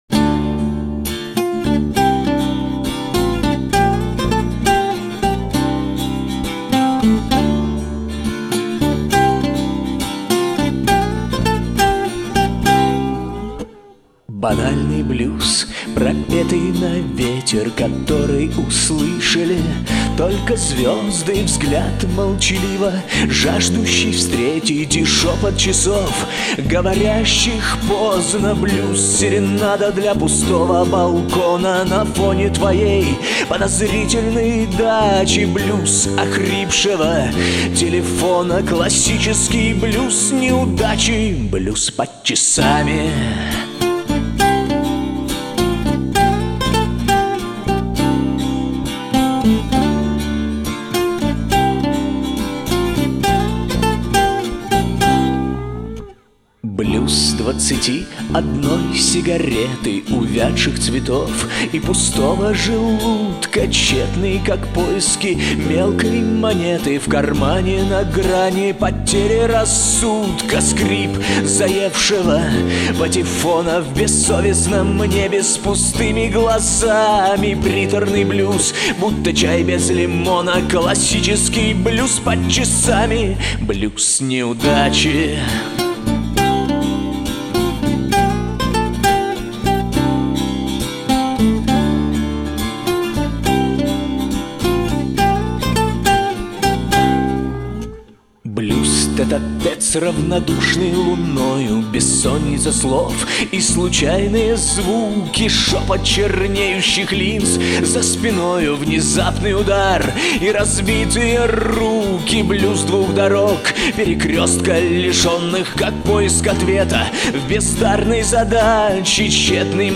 Авторская песня
Режим: Stereo